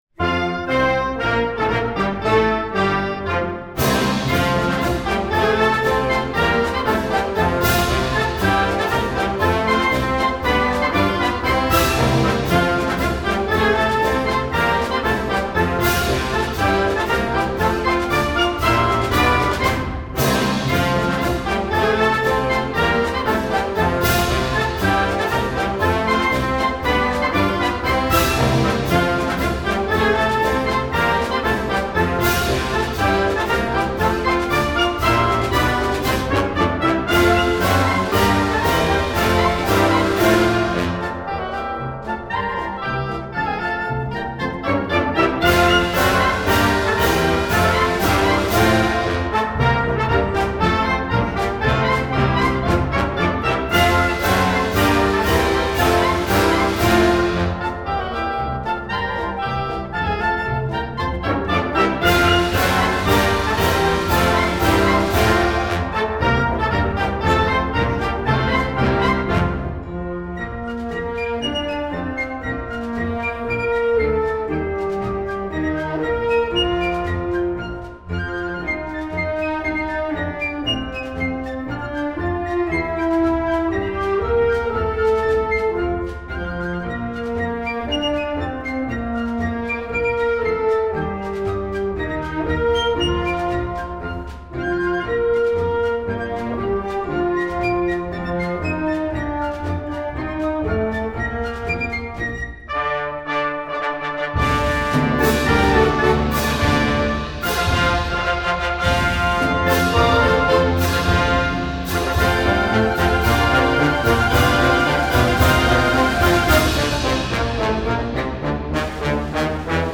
試聴サンプル